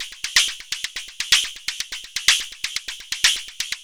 Index of /90_sSampleCDs/Transmission-X/Percussive Loops
tx_perc_125_lofiswish2.wav